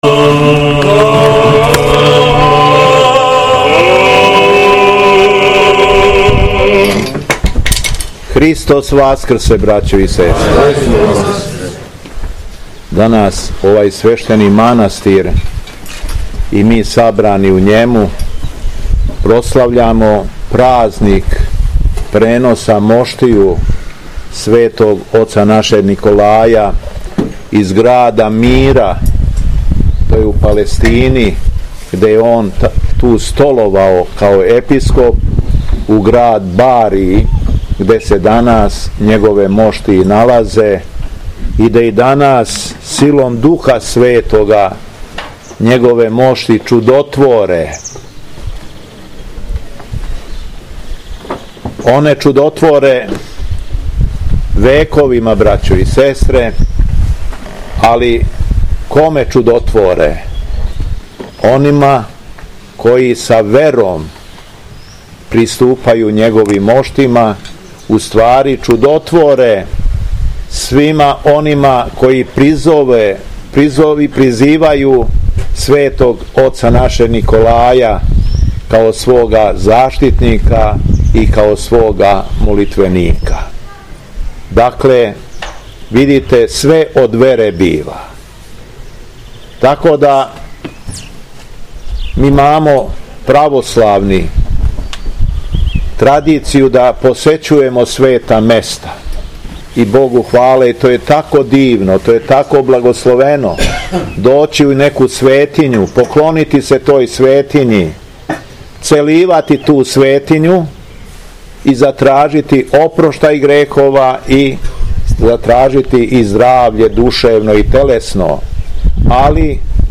ПРЕНОС МОШТИЈУ СВЕТОГ НИКОЛАЈА – ХРАМОВНА СЛАВА МАНАСТИРА НИКОЉЕ - Епархија Шумадијска
Беседа Његовог Високопреосвештенства Митрополита шумадијског г. Јована
Након прочитаног јеванђелског зачала верни народ богонадахнутом беседом поучио је владика Јован: